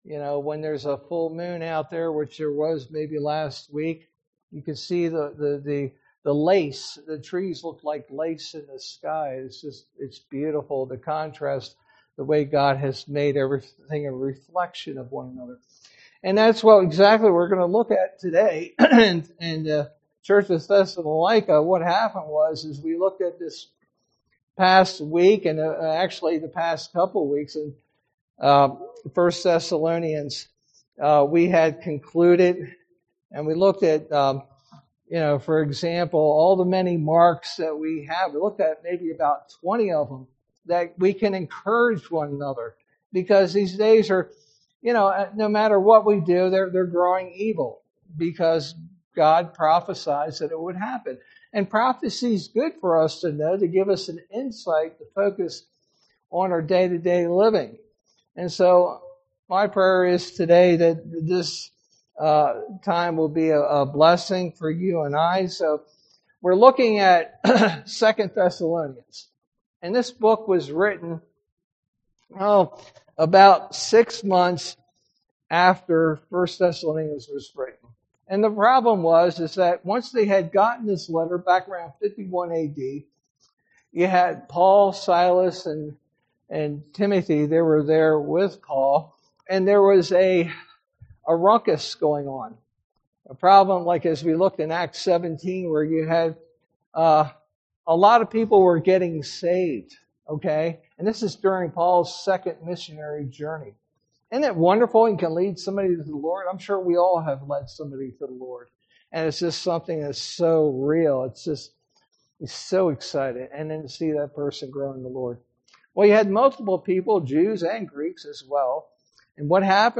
sermon verse: 2 Thessalonians 1:1-12